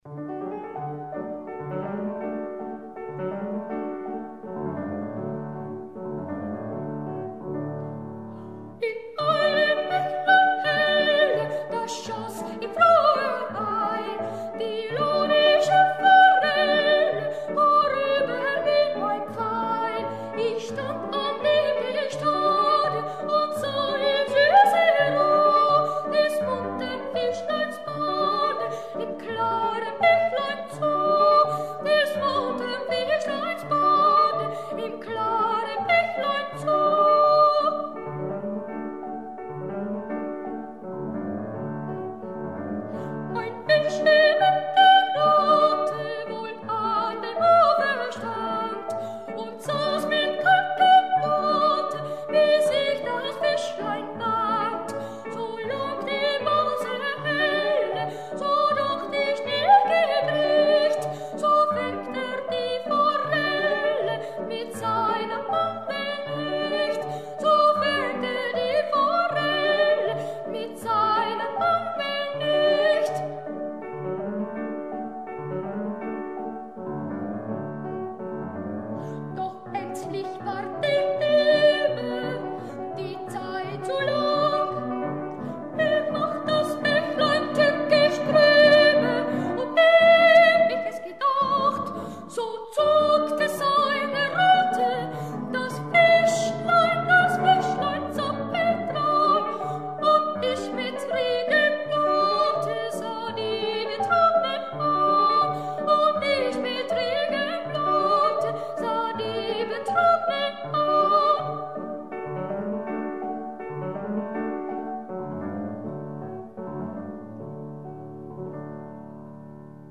现在大家欣赏到的是维也纳童声合唱团演唱的版本。